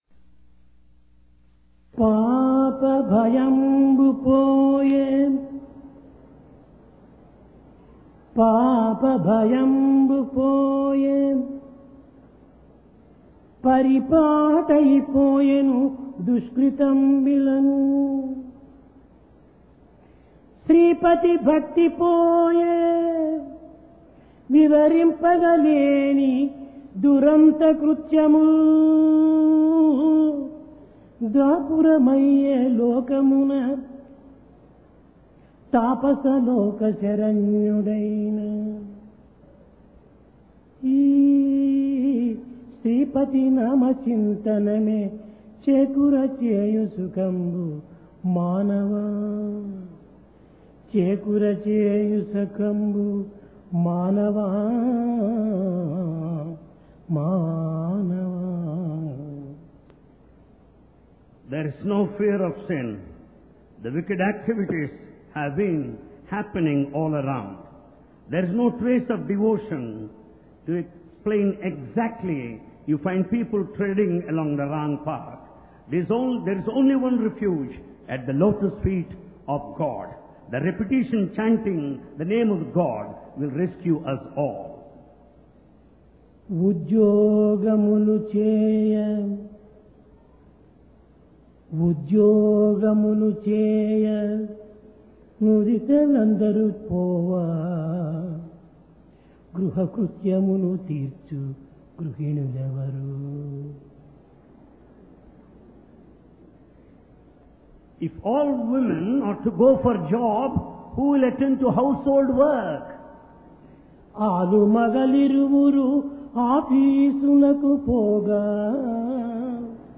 Divine Discourse of Bhagawan Sri Sathya Sai Baba, Sri Sathya Sai Speaks, Vol 29 (1996) Date: 19 November 1996 Occasion: Ladies Day